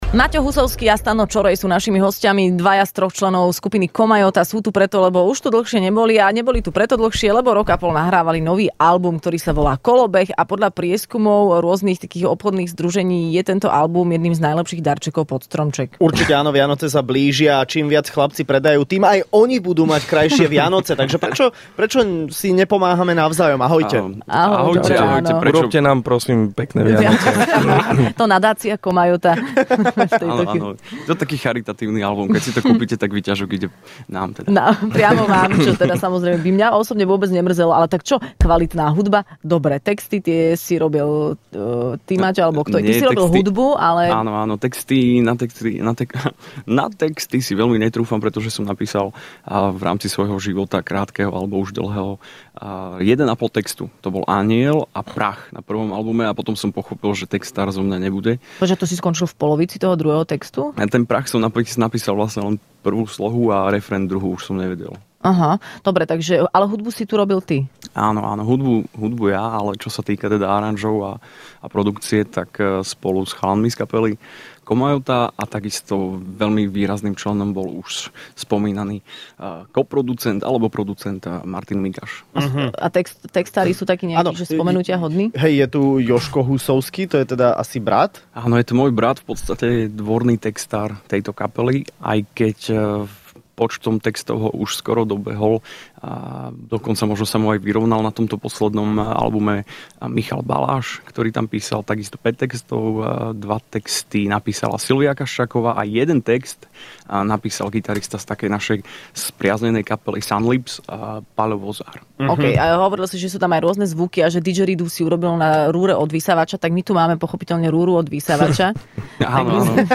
Hosťom v Rannej šou bola skupina Komajota, ktorá prišla predstaviť svoj nový album a v exkluzívnej premiére zahrali aj svoju novú pieseň...